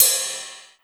• Ride Sound B Key 04.wav
Royality free ride sound sample tuned to the B note.
ride-sound-b-key-04-znf.wav